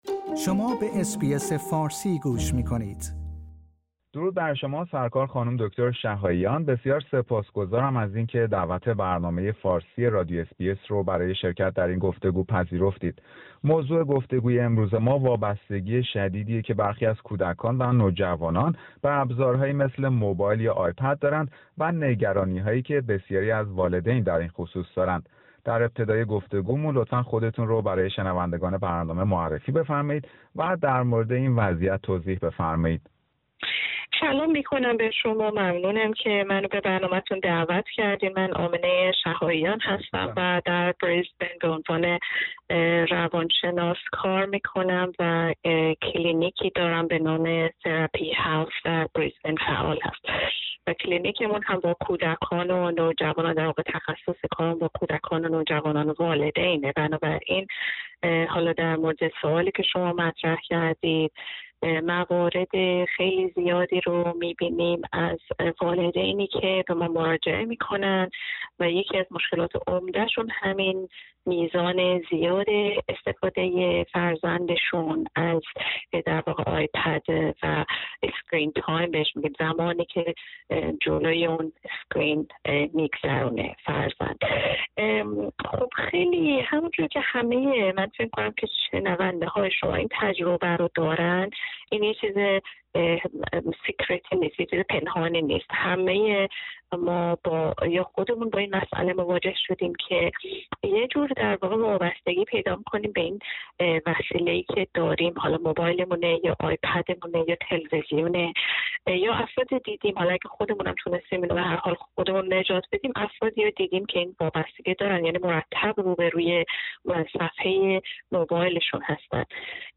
گفتگویی در مورد اهمیت نظارت بر مدت زمان استفاده کودکان از ابزارهای دیجیتال